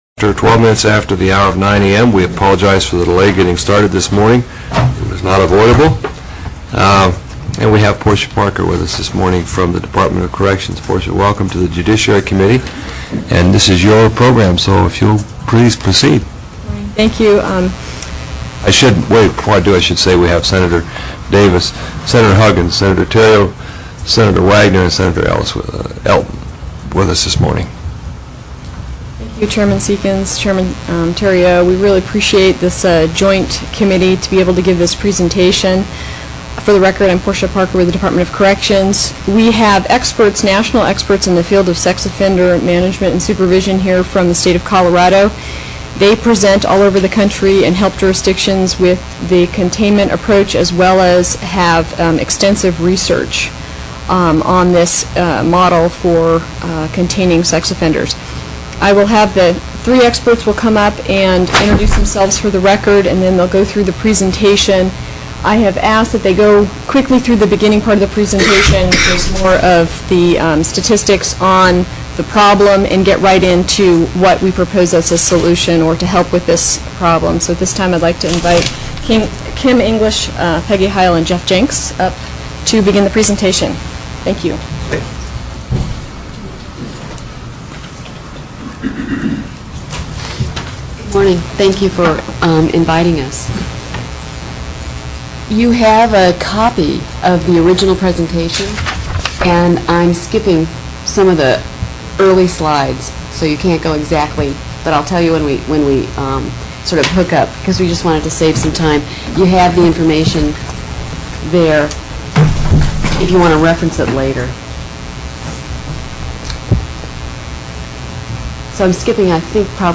03/16/2005 08:30 AM Senate STATE AFFAIRS